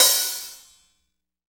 Index of /90_sSampleCDs/Roland L-CD701/KIT_Drum Kits 2/KIT_Whakker Kit
HAT REAL H09.wav